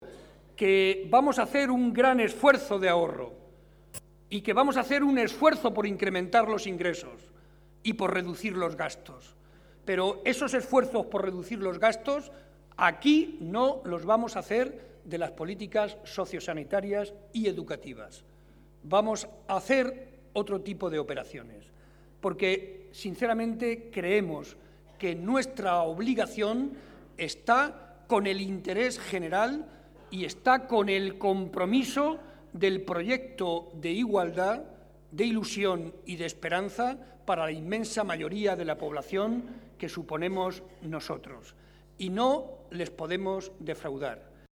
Estas son algunas de las manifestaciones que ha hecho esta noche el secretario regional del PSOE y presidente de Castilla-La Mancha, José María Barreda, durante el transcurso de la tradicional cena de Navidad del PSOE de Guadalajara y que ha congregado a más de 400 afiliados y simpatizantes.